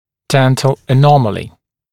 [‘dent(ə)l ə’nɔməlɪ][‘дэнт(э)л э’номэли]зубная аномалия